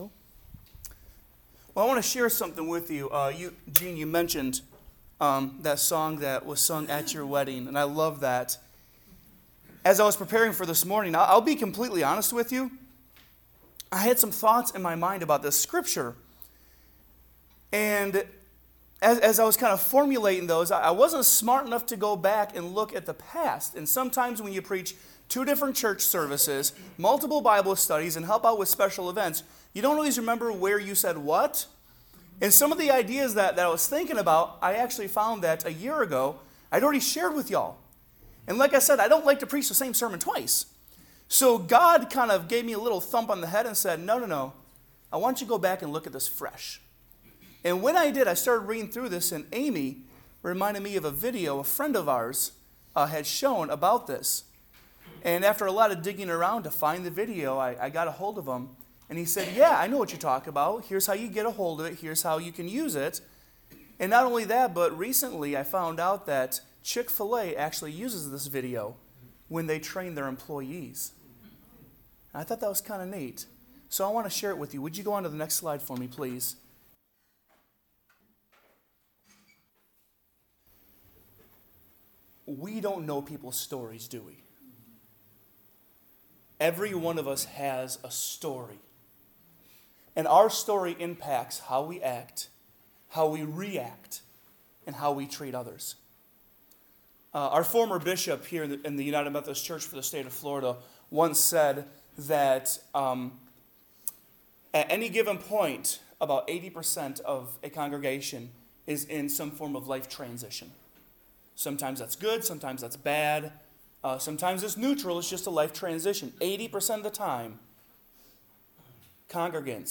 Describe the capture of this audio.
Salem United Methodist Church Sunday Worship